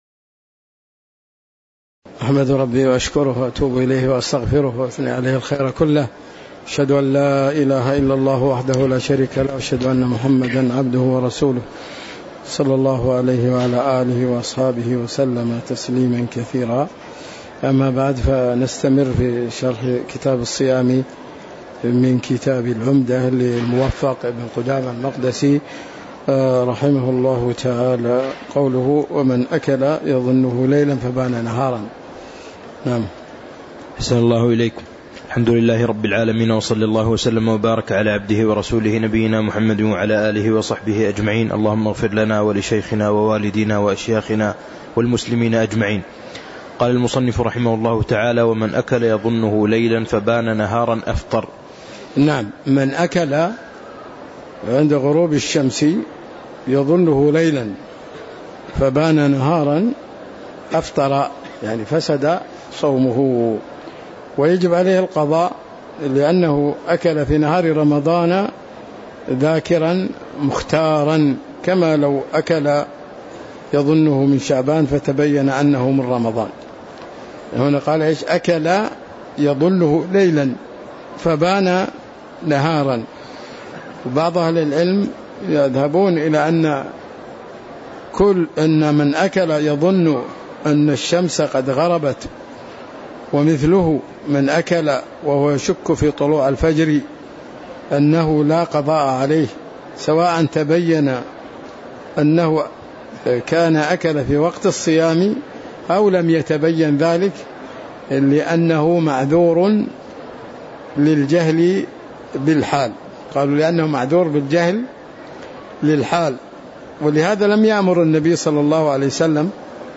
تاريخ النشر ٢٠ شعبان ١٤٤٦ هـ المكان: المسجد النبوي الشيخ